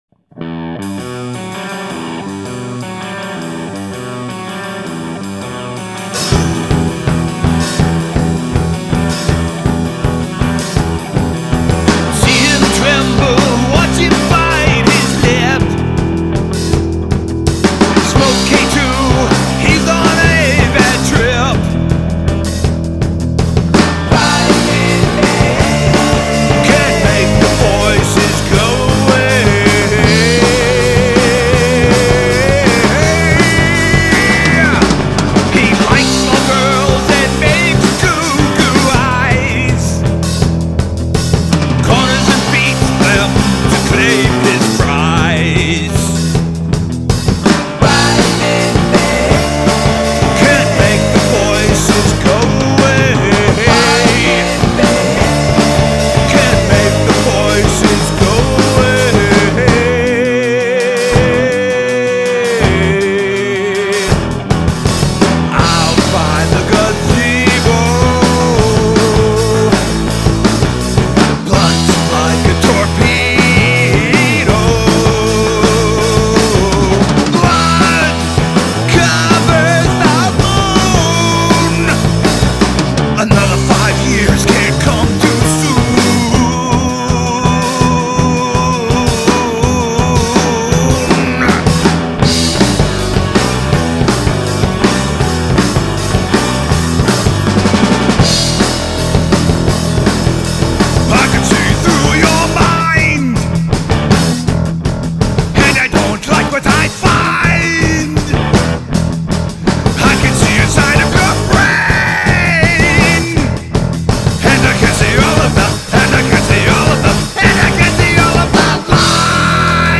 drums
This is huge and creepy.